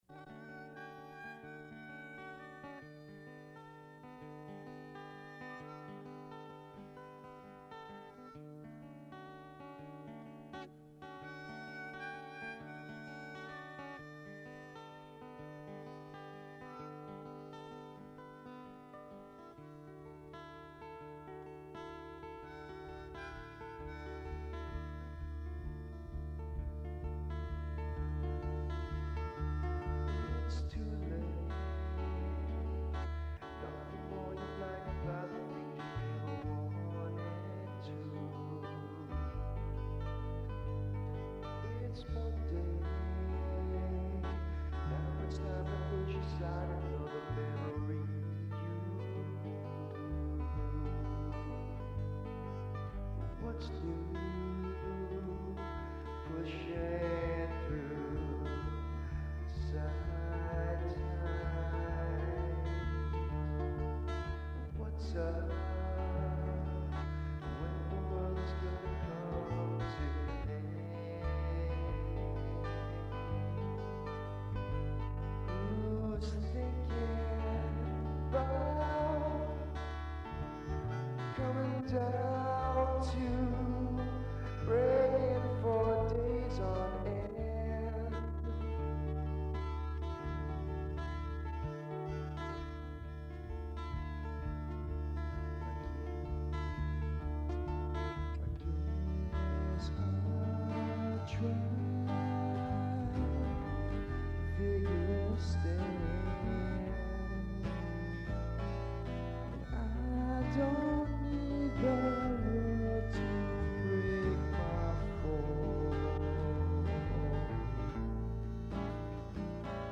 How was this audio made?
Recorded at Berklee Studio B, Boston, MA-1994